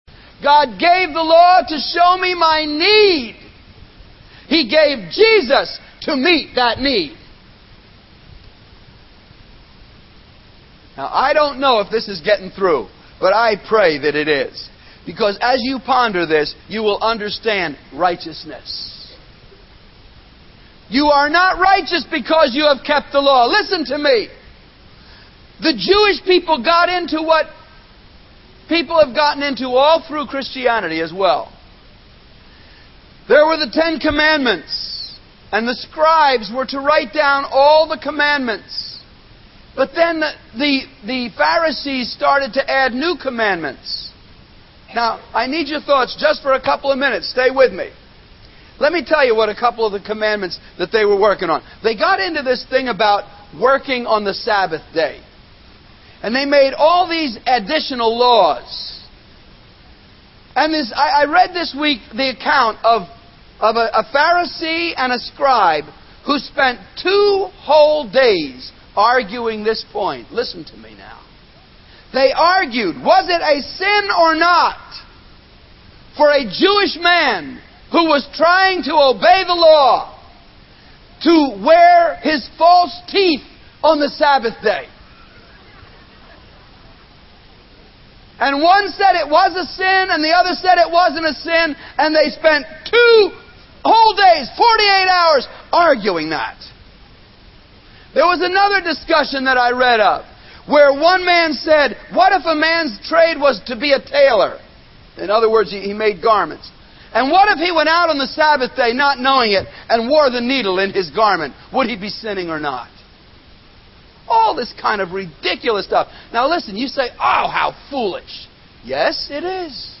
Sermon Archives - The Beautitudes